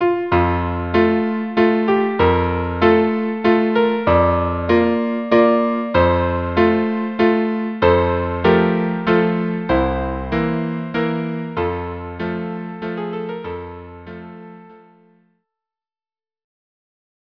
Instrumentation: Violin 1; Violin 2; Viola; Cello